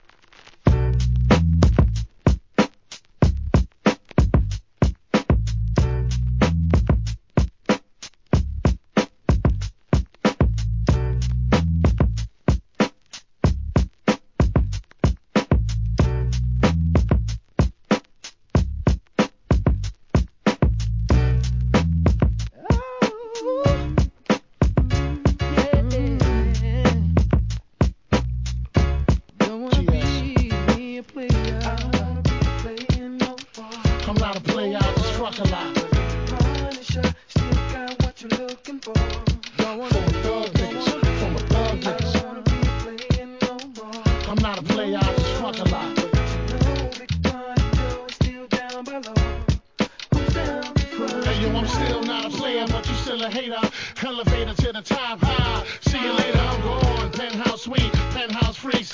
HIP HOP/R&B
DJには使い易いようにイントロ、アウトロがエディットされた人気シリーズ